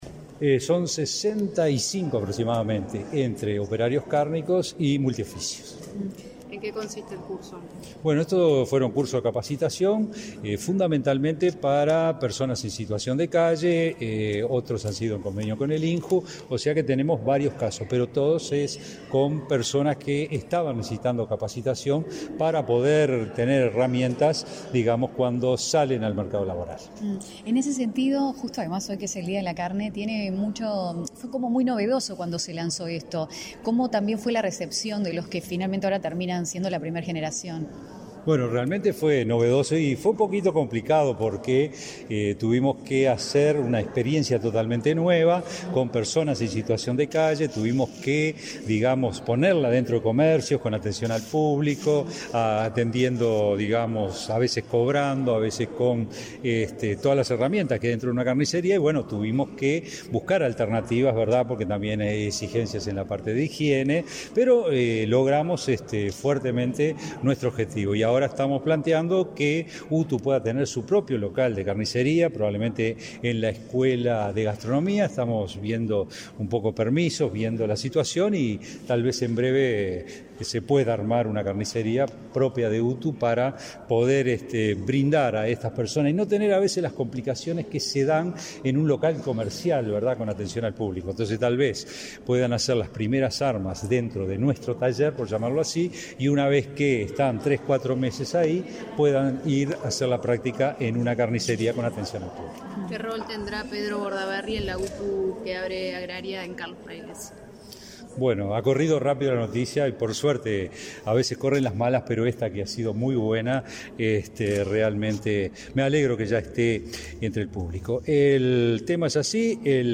Declaraciones a la prensa del director general de UTU, Juan Pereyra
Declaraciones a la prensa del director general de UTU, Juan Pereyra 29/05/2023 Compartir Facebook X Copiar enlace WhatsApp LinkedIn Tras participar en la entrega de certificados de capacitación en multioficios del Ministerio de Desarrollo Social y la UTU, este 29 de mayo, el director Juan Pereyra realizó declaraciones a la prensa.